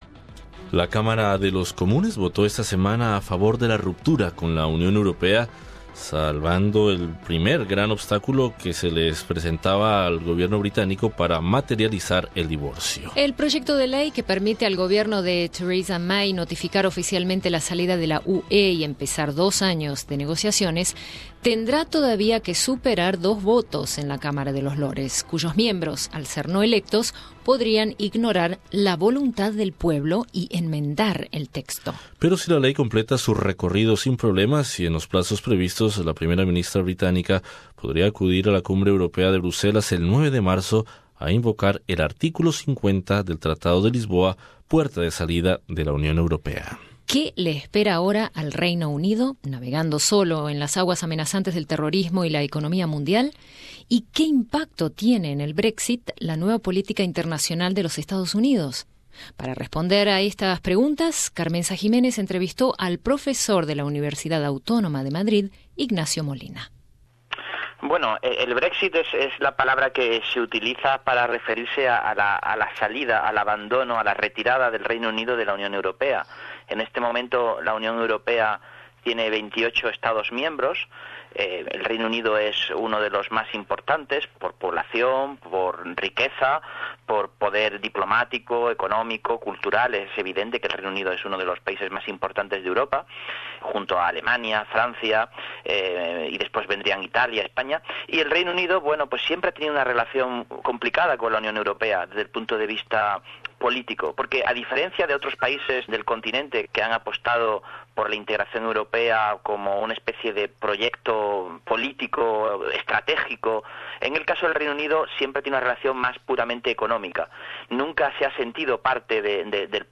¿Qué le espera ahora al Reino Unido navegando solo en las aguas amenazantes del terrorismo y qué impacto tiene en el Brexit la nueva política internacional de los Estados Unidos? Entrevista con el profesor de ciencias políticas de la Universidad Autónoma de Madrid